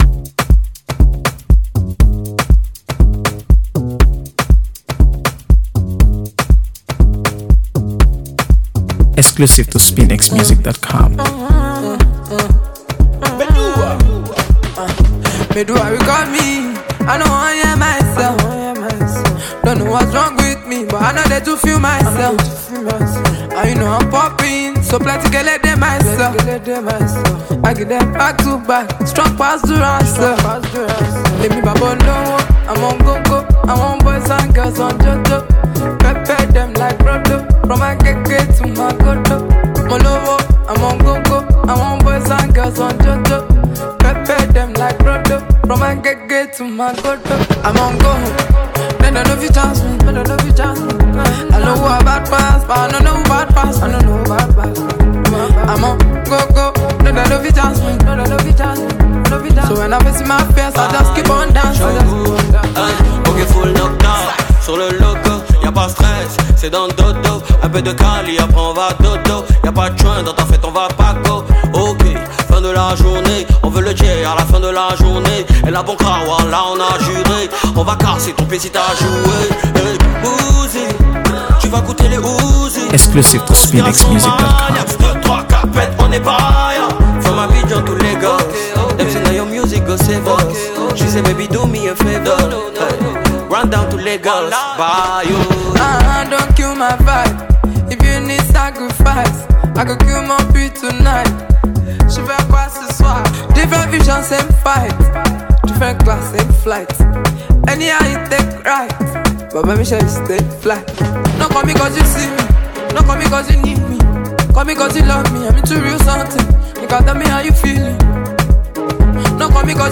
AfroBeats
blends captivating melodies with catchy lyrics